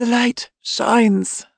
女祭司
德得尔是一名辅助，虽然技能强力但升级速度较慢，不是特别受玩家的欢迎。但满级后身板很抗打，可以治疗甚至复活友军，语音风格比较激昂，振奋人心。